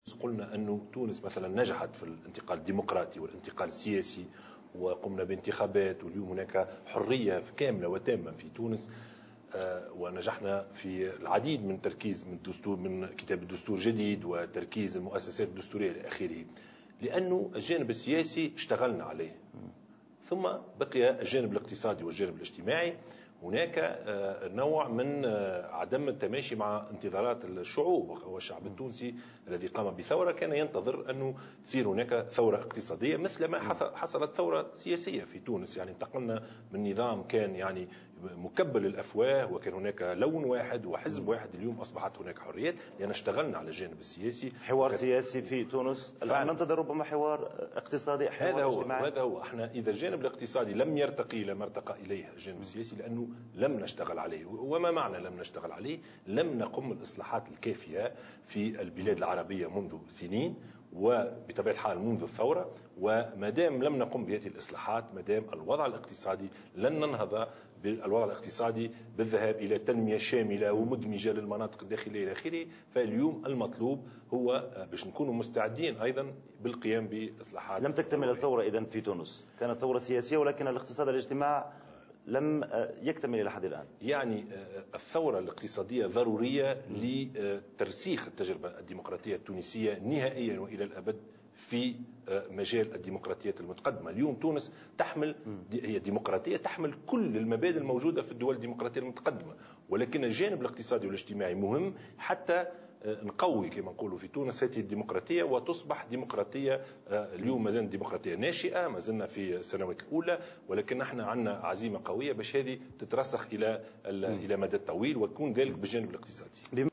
قال رئيس الحكومة يوسف الشاهد في حوار لقناة "فرانس 24" إن تونس نجحت في الانتقال الديمقراطي والسياسي وفي كتابة دستور جديد وتركيز المؤسسات الدستورية، لكن بقي الجانبان الاقتصادي والاجتماعي دون انتظارات الشعب التونسي.